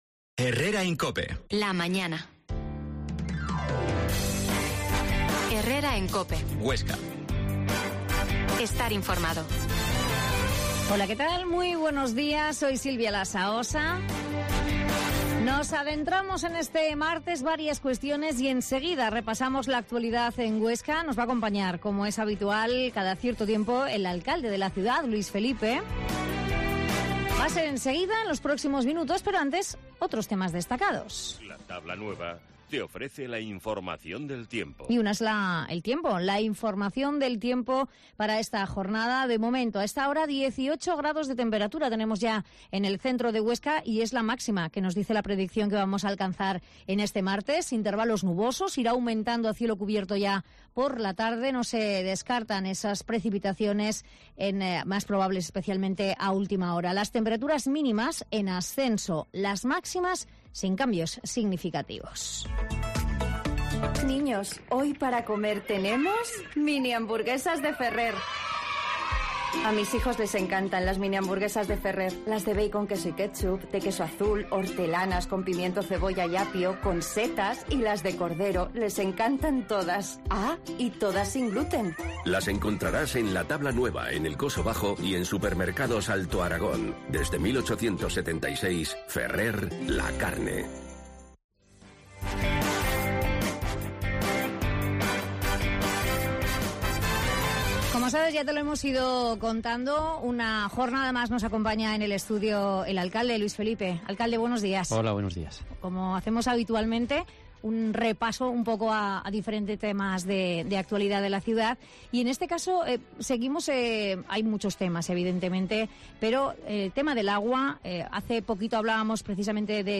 Herrera en COPE Huesca 12.50h Entrevista al alcalde de Huesca, Luis Felipe